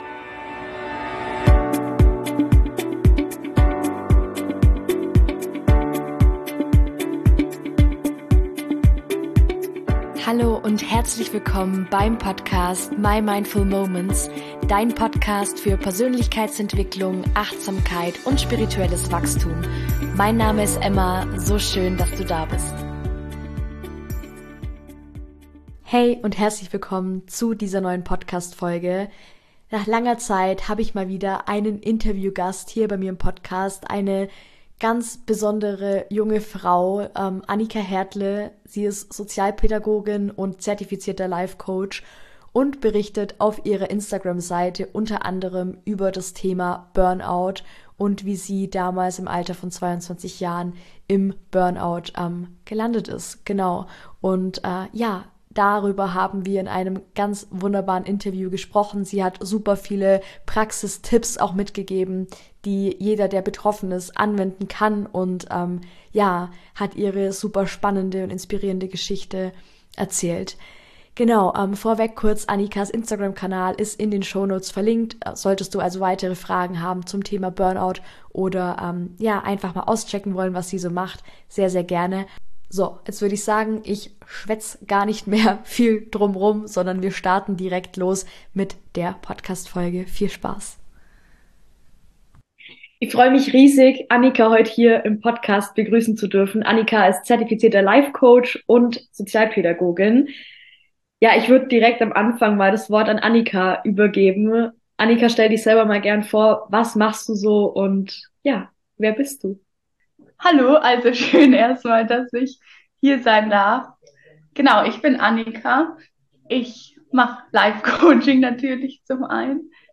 Was Burnout WIRKLICH ist - Interview